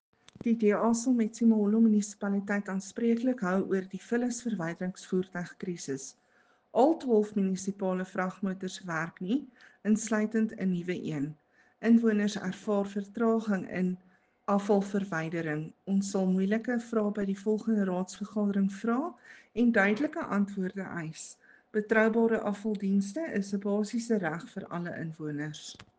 Afrikaans soundbites by Cllr Ruanda Meyer and